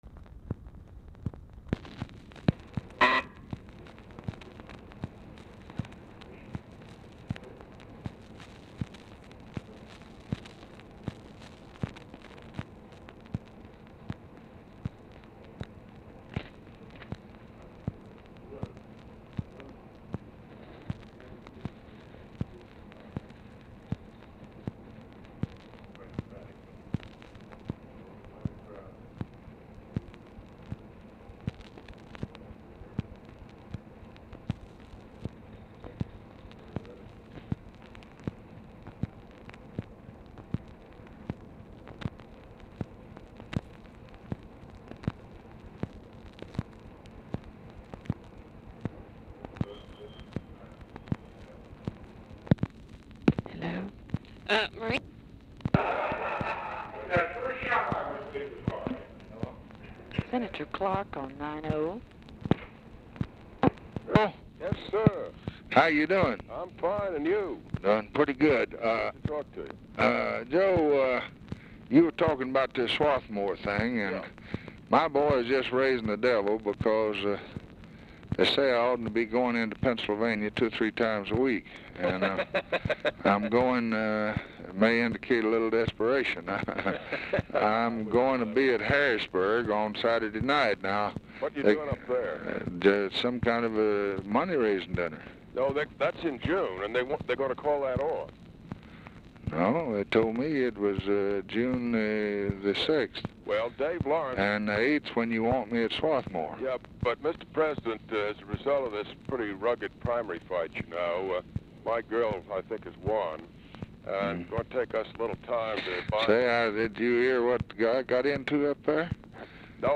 Telephone conversation # 3333, sound recording, LBJ and JOSEPH CLARK, 5/4/1964, 5:40PM
OFFICE CONVERSATION PRECEDES CALL; LBJ ON HOLD 1:00
Format Dictation belt